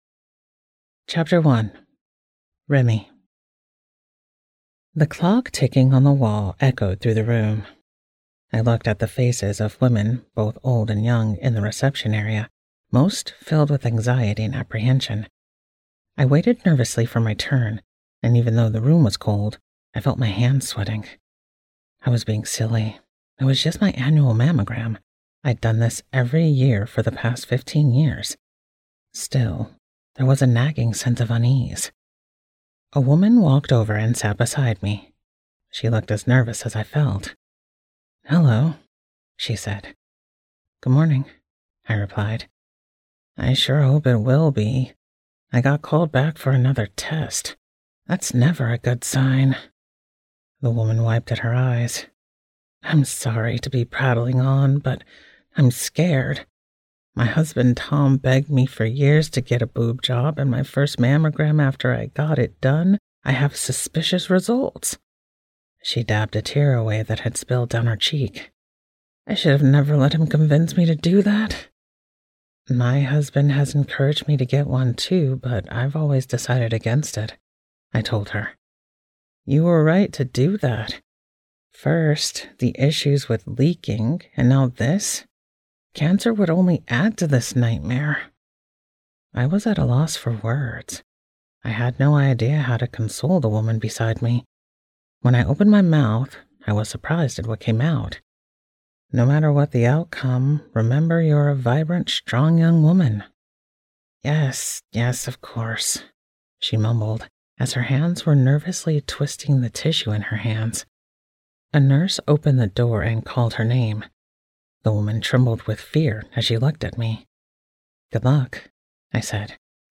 Heart Strings Attached by Ali Spooner & Annette Mori [Audiobook]